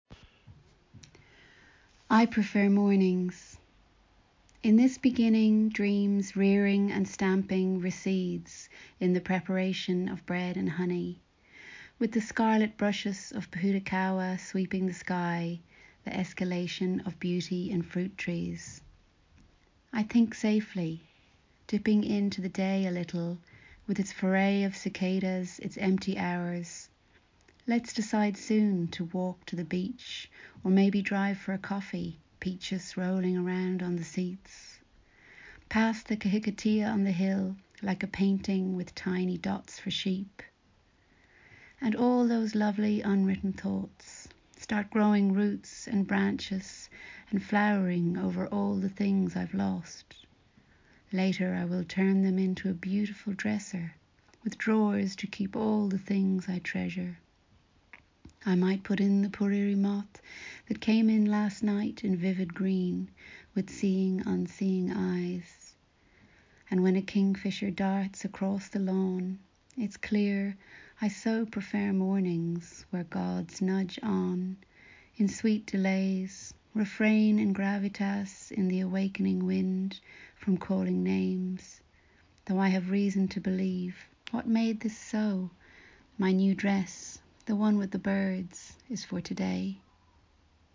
reads